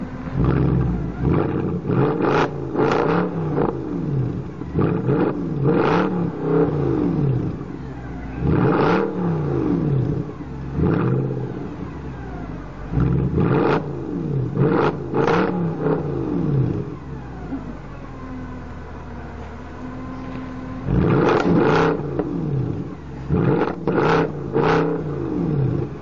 P O L I C E 00:25 < P O L I C E Sound Effect Message 00:05 < Sound Effect Message Alarm Tone 02 00:26 < Alarm Tone 02 wistle 00:02 < wistle Extreme Alarm Clock 00:30 < Extreme Alarm Clock SHOW ALL SIMILAR